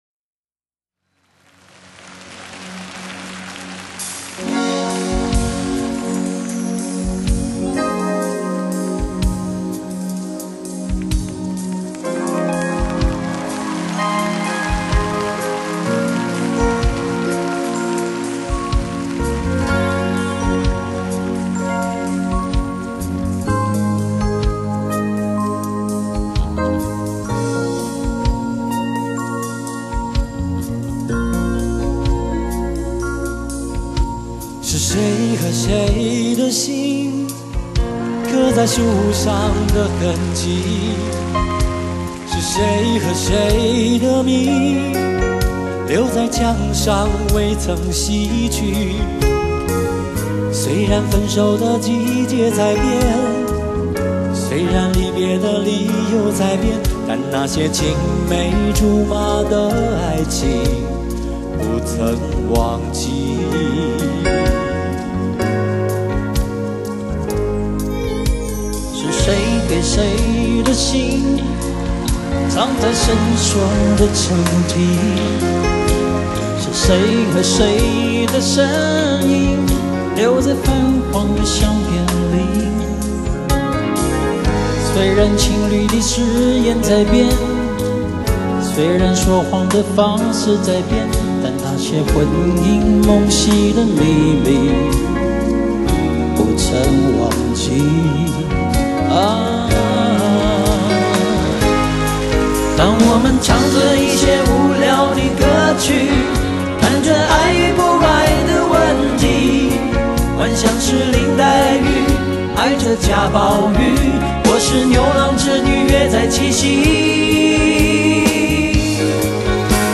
十足的現場感與衆不同，輕鬆親切活潑，將氛圍推向高潮。